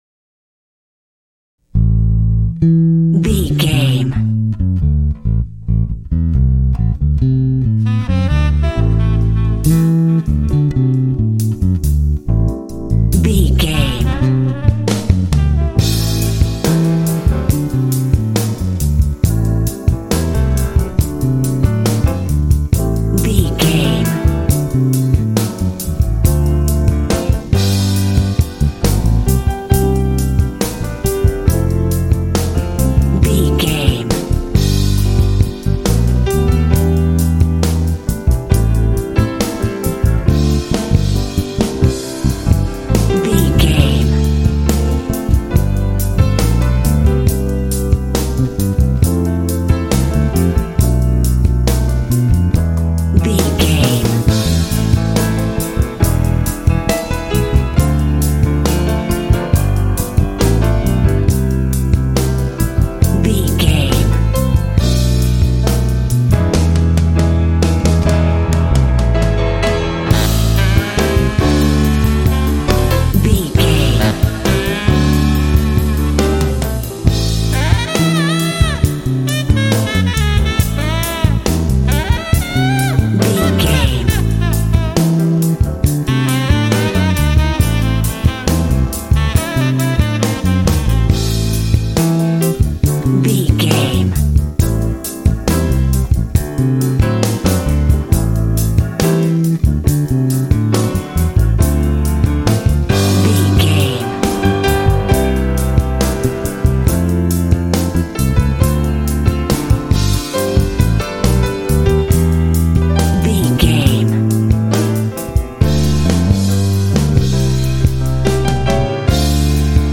Aeolian/Minor
Slow
smooth
saxophone
bass guitar
drums
piano
jazz